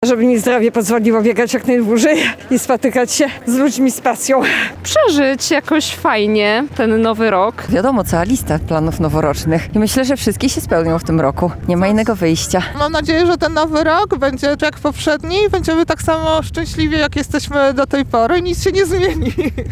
Nasza reporterka spytała spacerowiczów o plany, postanowienia i życzenia noworoczne.